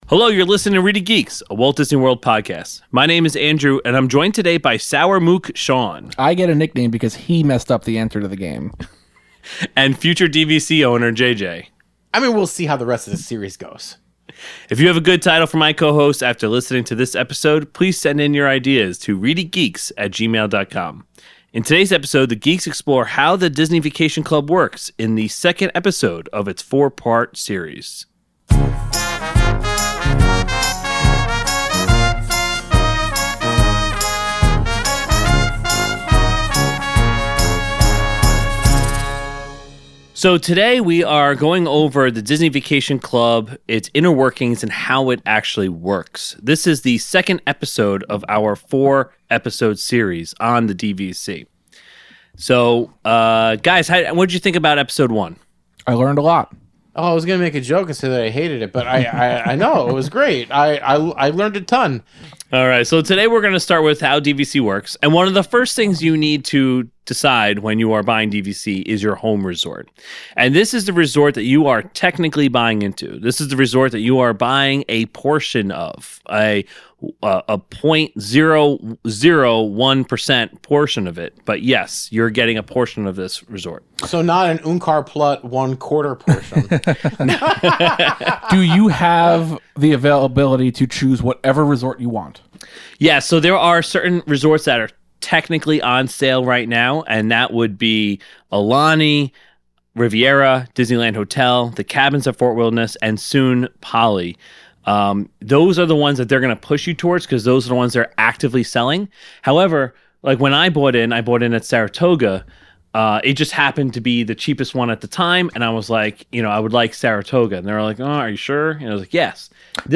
Then, the Geeks play a round of WDW Trivia.